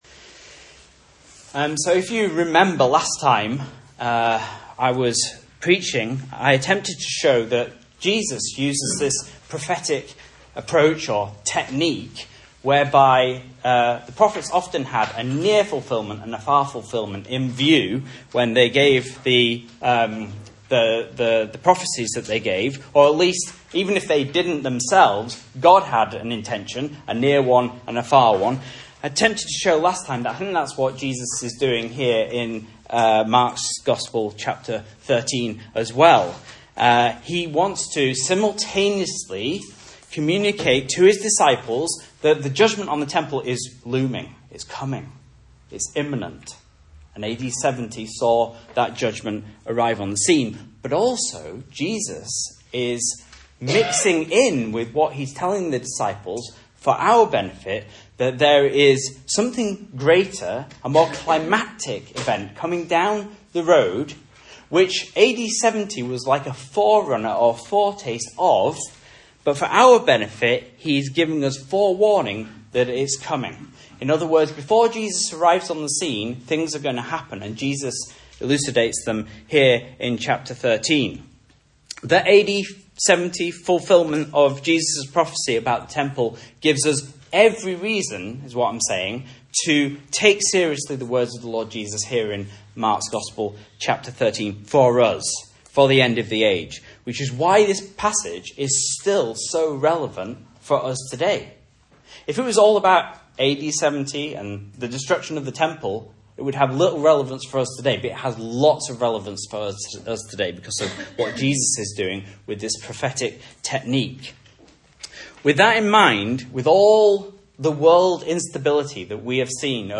Message Scripture: Mark 13 | Listen